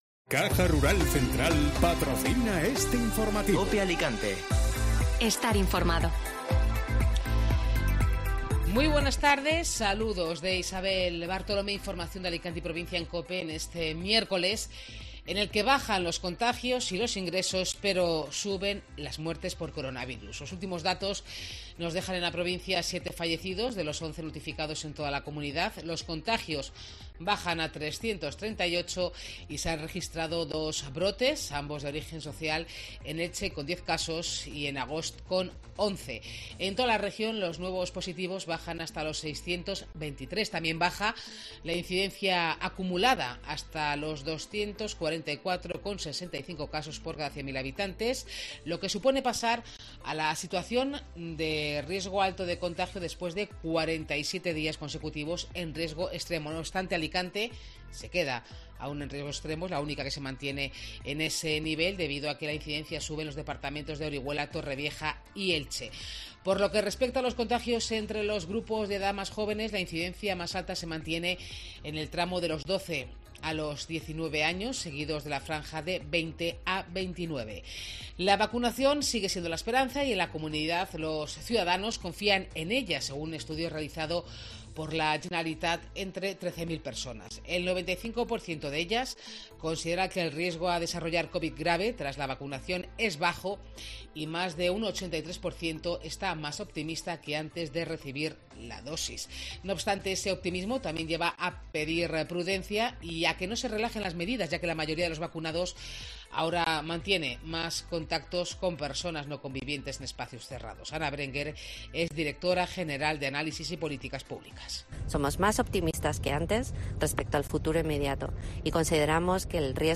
Informativo Mediodía COPE (Miércoles 25 de agosto)